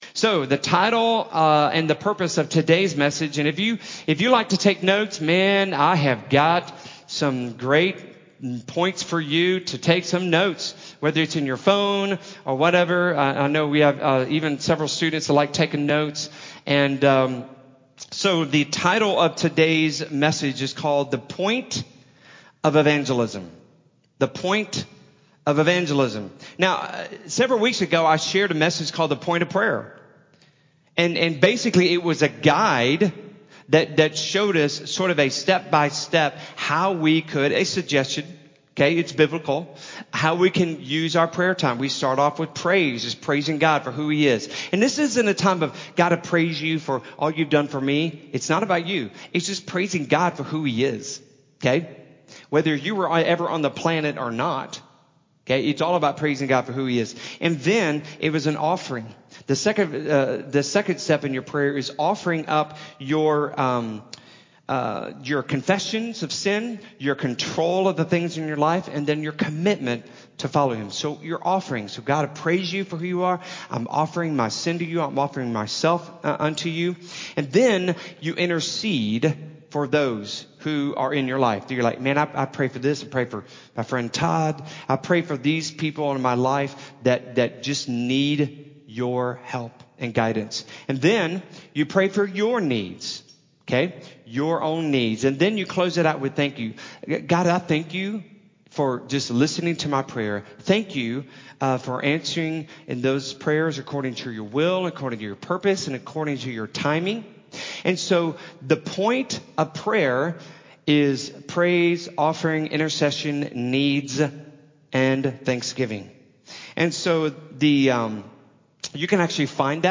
The-POINT-of-Evangelism-Sermon-Audio-CD.mp3